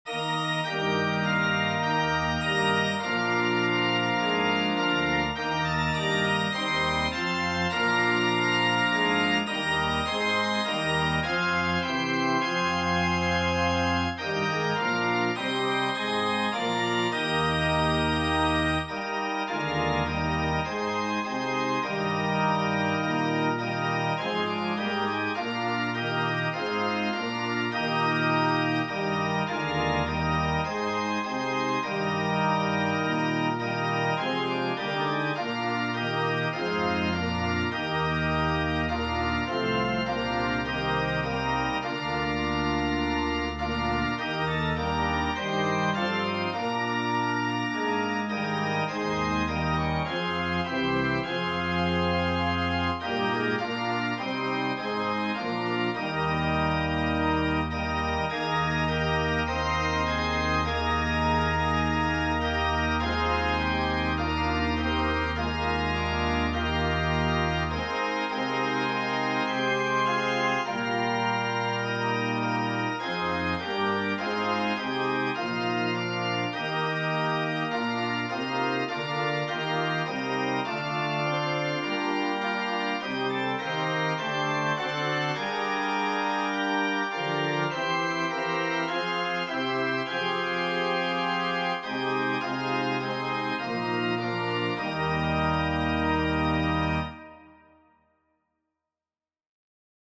Voicing/Instrumentation: Organ/Organ Accompaniment